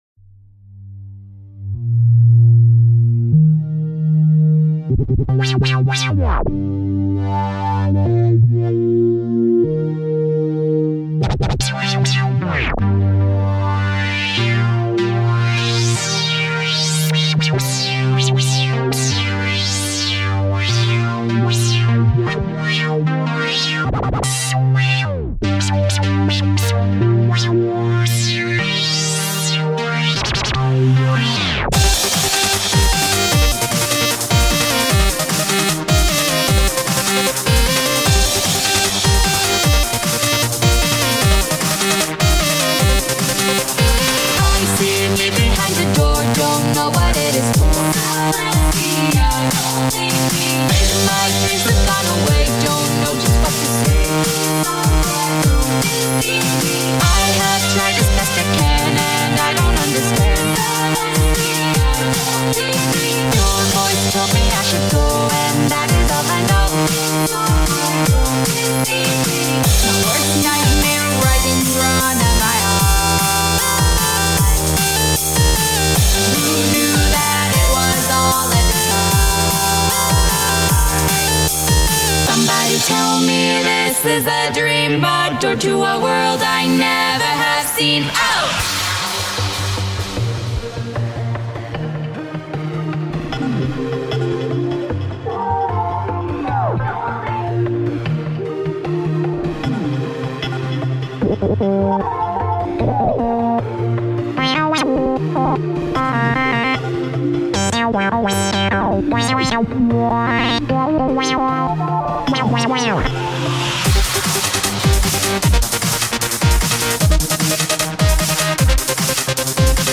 sexy synths solos